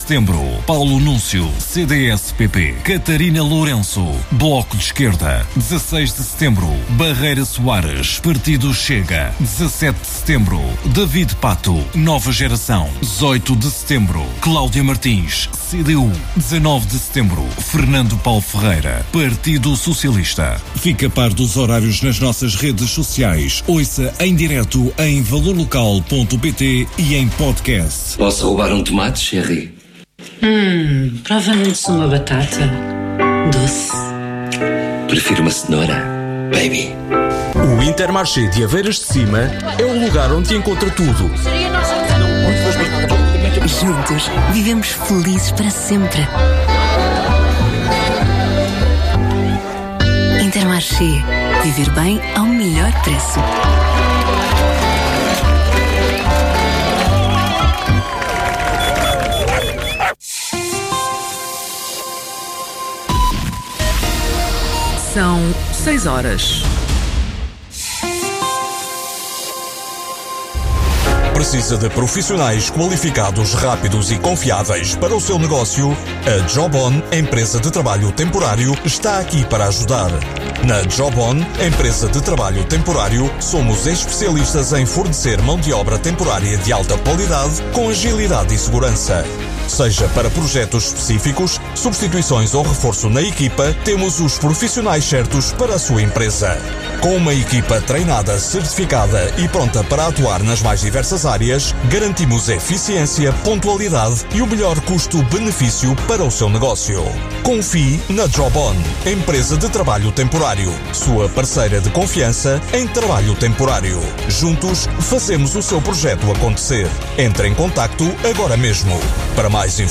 Autárquicas 2025 - Câmara Municipal de Vila Franca de Xira- Entrevista